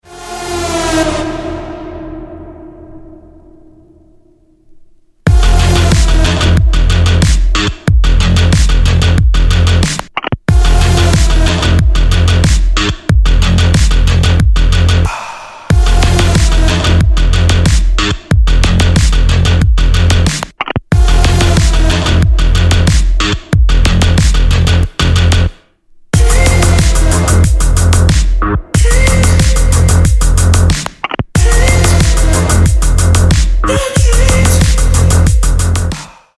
Громкие Рингтоны С Басами
Рингтоны Без Слов
Рингтоны Электроника